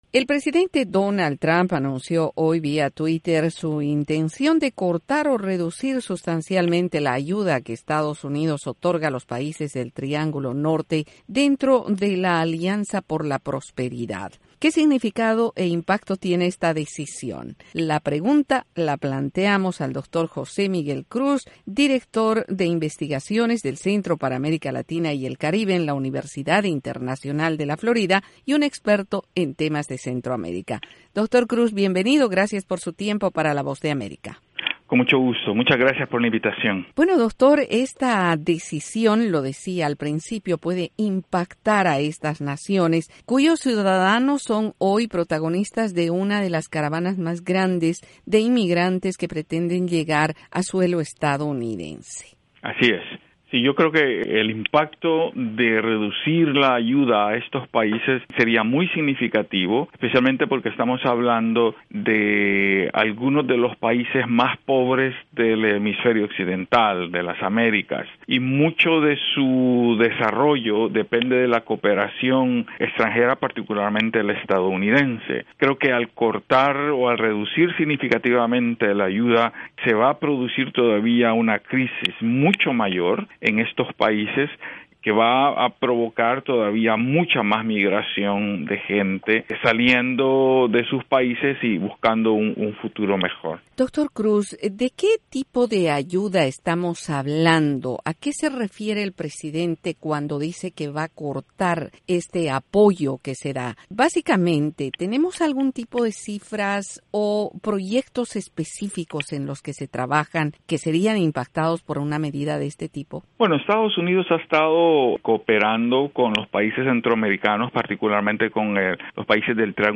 La Voz de América entrevistó al experto en temas de Centroamérica